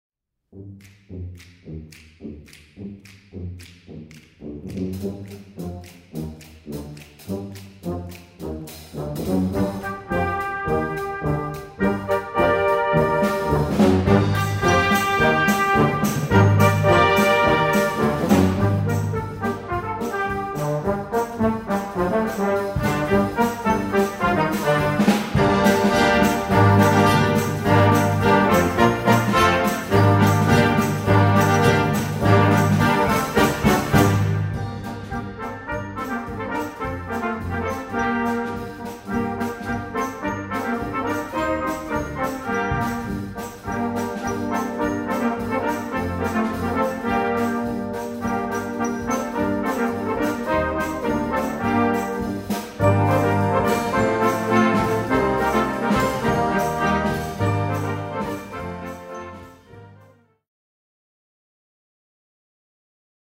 Komponist: Volksweise
Besetzung: Blasorchester